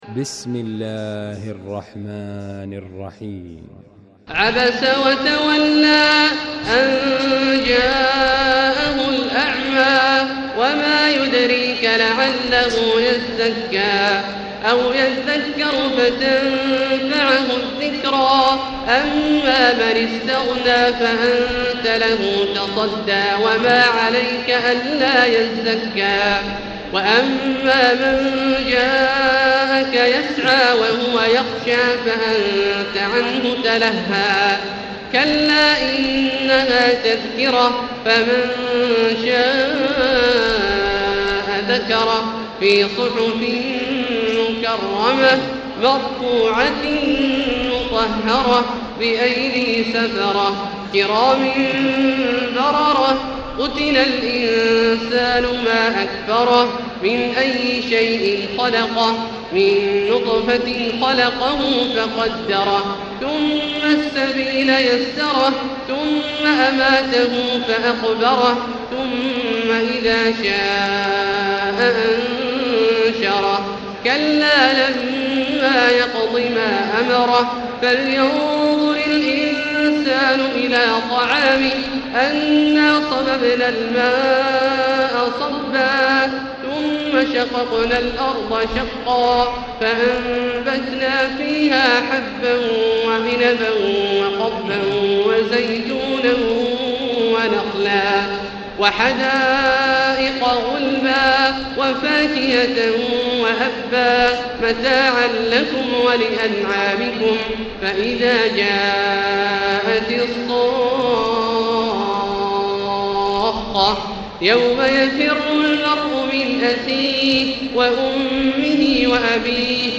المكان: المسجد الحرام الشيخ: فضيلة الشيخ عبدالله الجهني فضيلة الشيخ عبدالله الجهني عبس